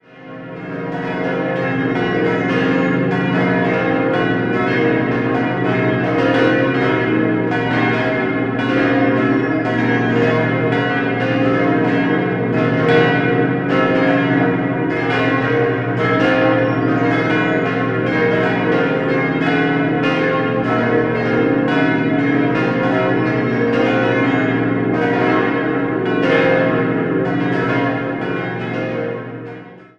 5-stimmiges Geläute: b°-c'-es'-f'-g'